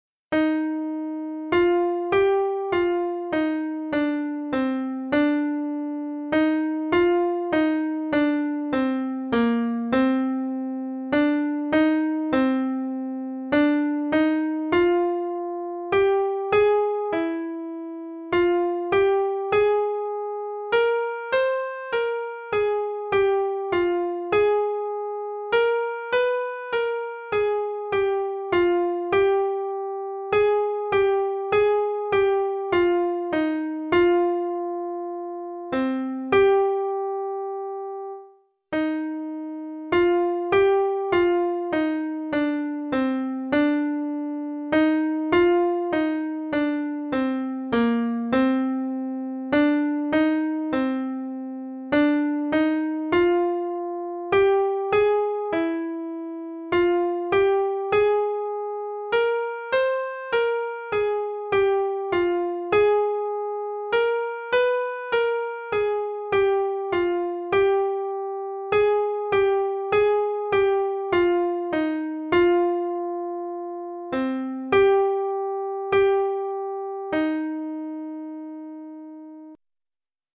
File MIDI